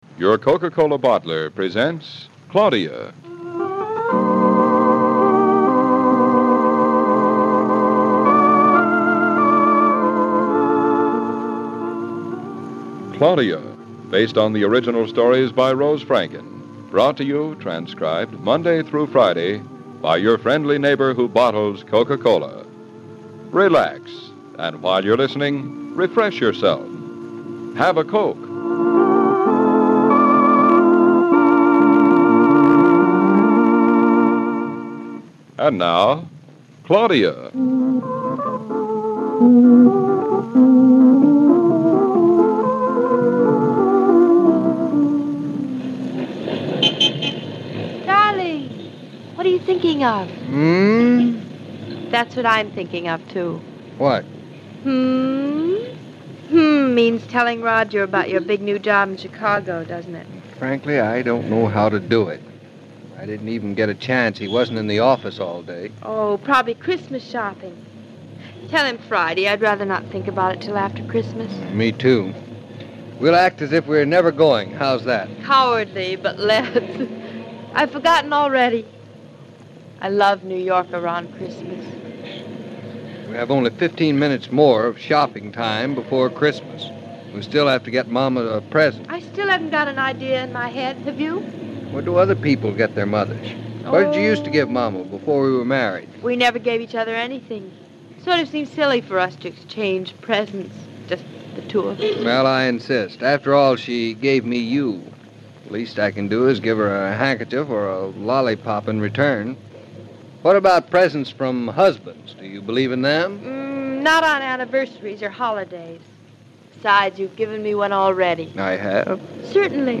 Coke radio soap 'Claudia' - episode first aired on Christmas Eve in 1924
Just like the detergent manufacturers whose sponsoring of soap operas lent them their name, Coke was bringing original drama to radio. Enjoy this 15’ episode of the ‘Claudia’ drama, produced by the D'Arcy ad Agency in St. Louis, first aired on Christmas Eve 1924.